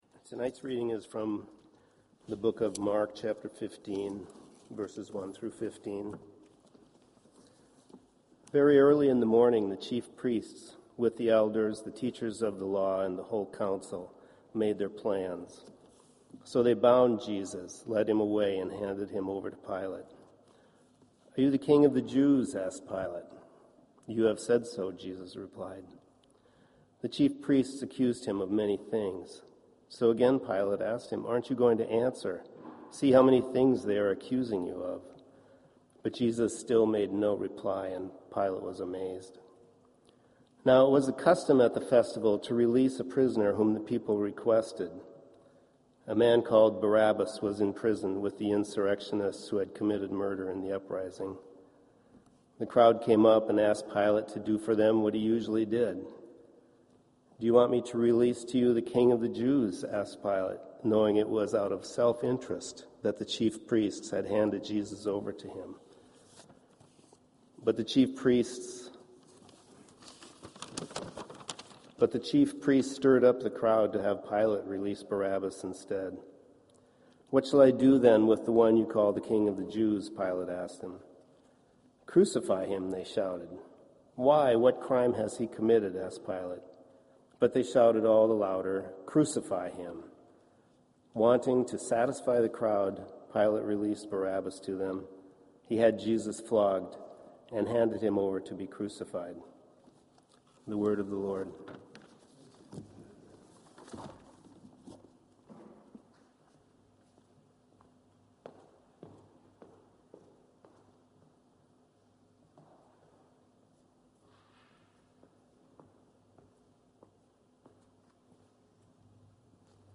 Sermons .